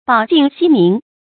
保境息民 bǎo jìng xī mín
保境息民发音